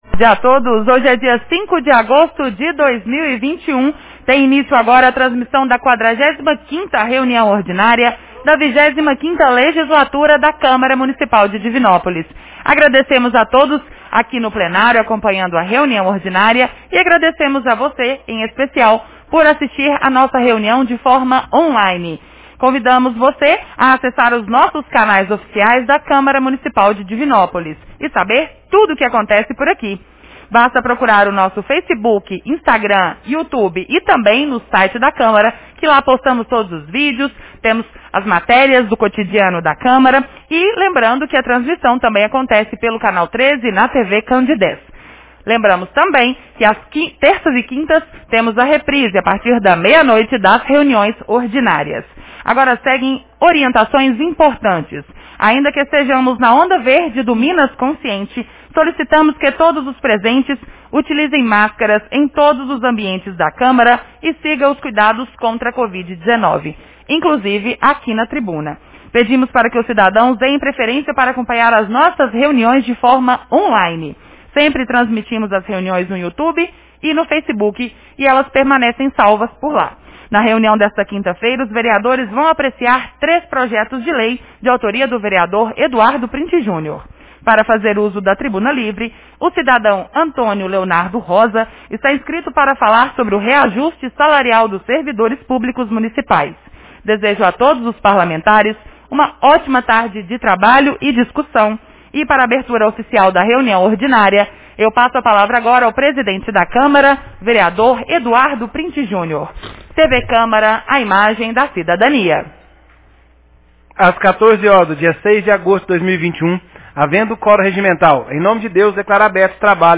Reunião Ordinária 45 de 05 de agosto 2021